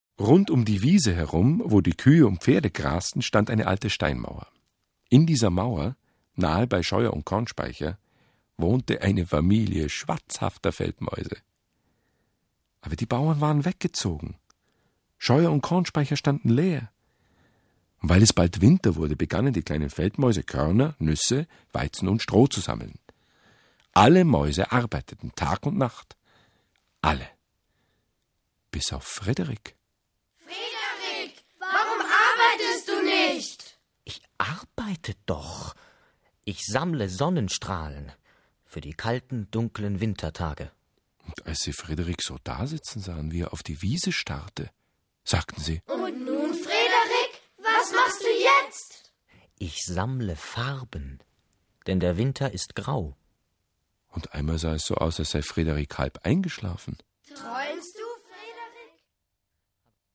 Ein Hörspiel mit Musik
Frederick und seine Freunde - Alexander und die Aufziehmaus, Matthias und sein Traum, Nicolas, Geraldine und die Mauseflöte. Ein musikalisches Hörspiel mit vielen Liedern.